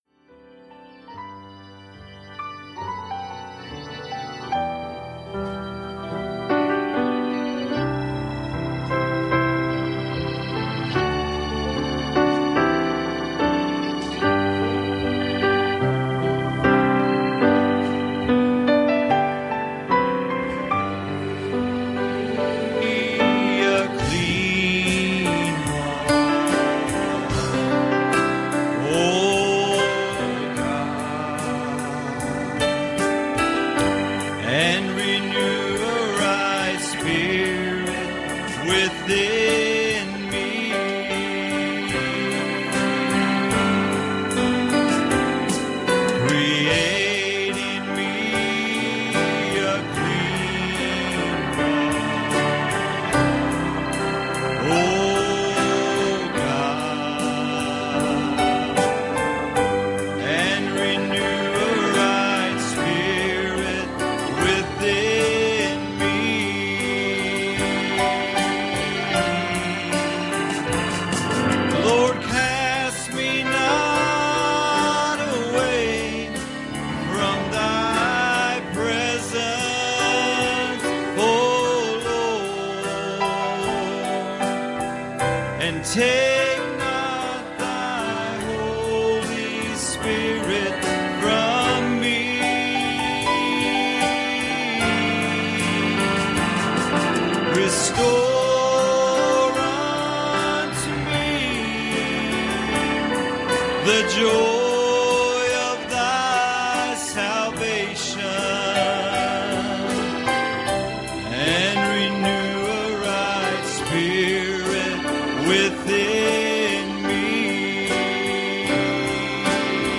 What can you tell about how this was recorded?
Service Type: Wednesday Evening